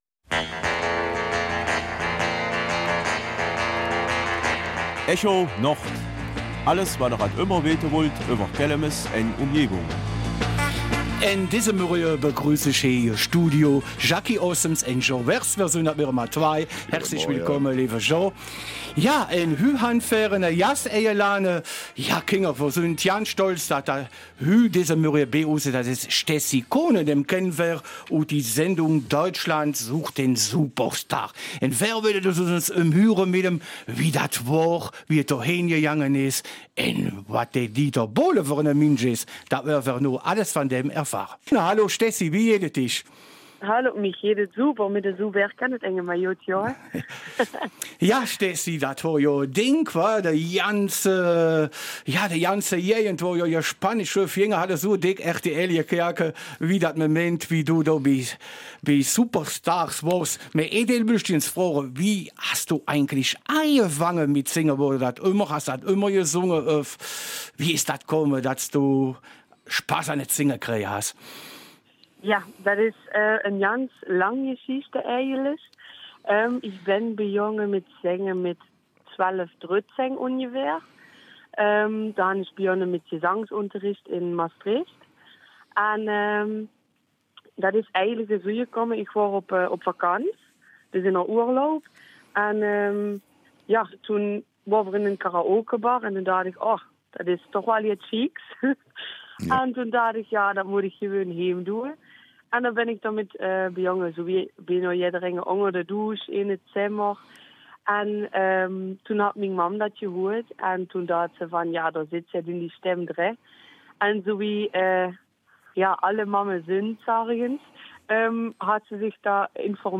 Die beiden Moderatoren wollen wissen, wie es ihr ergangen ist und welche Erfahrung sie mit der Jury gemacht hat.